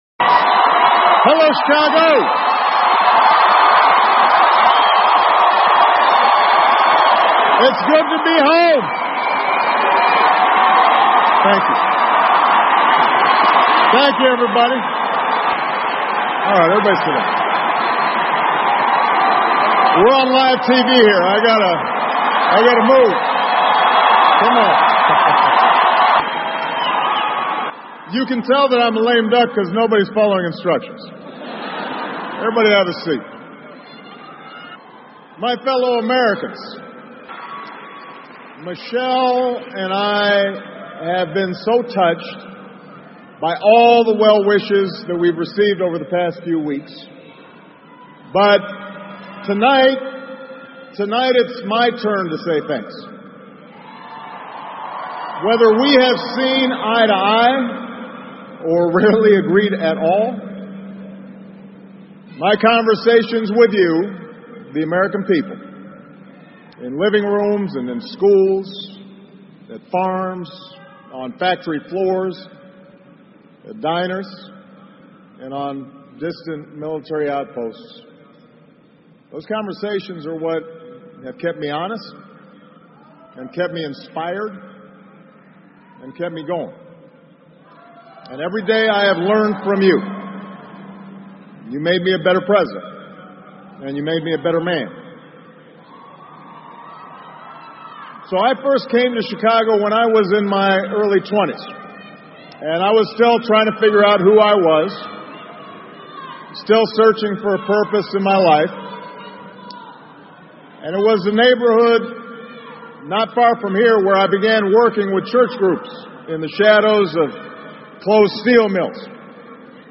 奥巴马每周电视讲话：美国总统奥巴马告别演讲(1) 听力文件下载—在线英语听力室